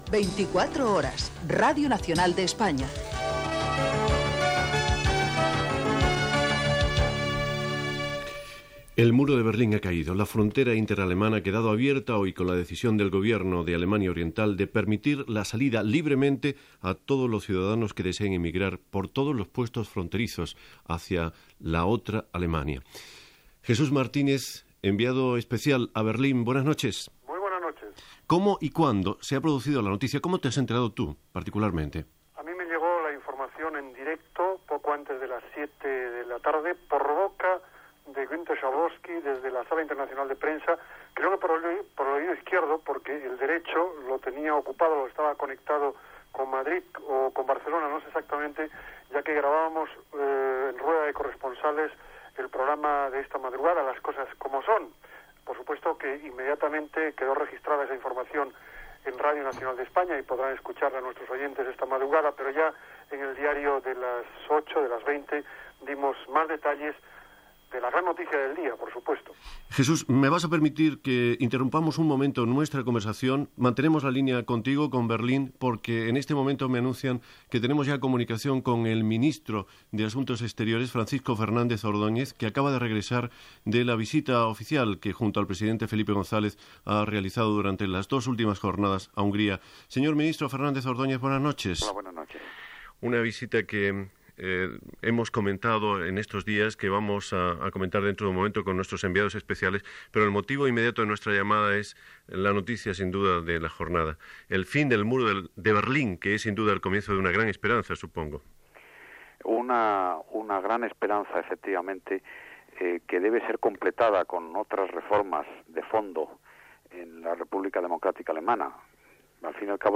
Careta del programa, inormació de la caiguda del mur de Berlín, que separava l'Alemanya Democràtica de l'Alemanya Federal. Connexió amb Berlín i declaracions del ministre d'assumptes estrangers espanyol, Francisco Fernández Ordóñez
Informatiu